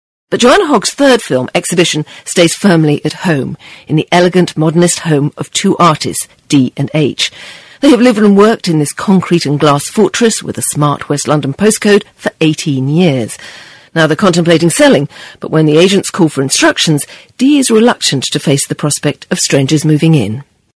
【英音模仿秀】梦想之路 听力文件下载—在线英语听力室